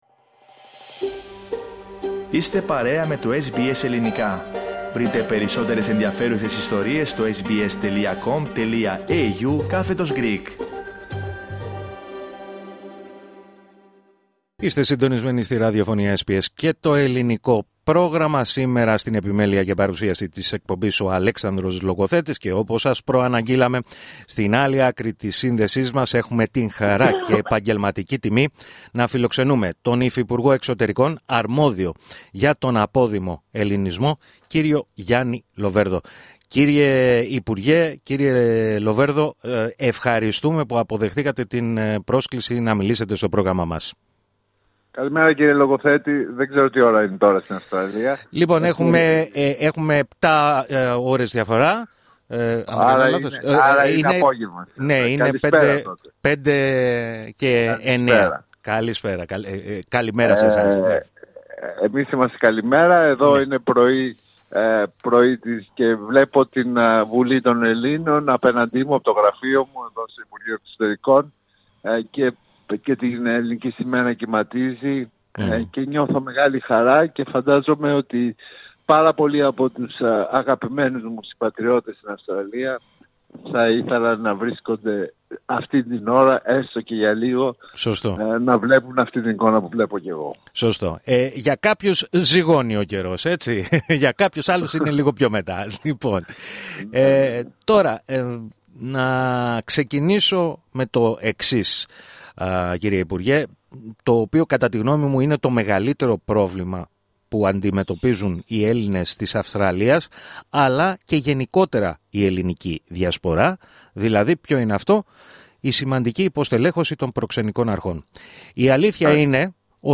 Ο υφυπουργός Εξωτερικών της Ελλάδας, αρμόδιος για θέματα Απόδημου Ελληνισμού, Γιάννης Λοβέρδος, παραχώρησε αποκλειστική συνέντευξη για την Αυστραλία, στο Ελληνικό Πρόγραμμα της ραδιοφωνίας SBS, αναφέροντας, μεταξύ άλλων, ότι θα πραγματοποιήσει μεγάλη περιοδεία στην Αυστραλία, τον προσεχή Οκτώβριο.